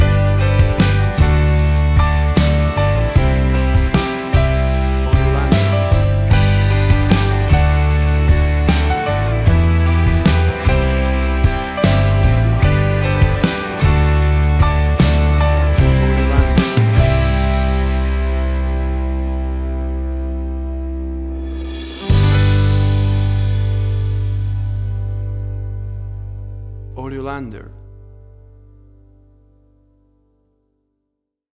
Tempo (BPM): 76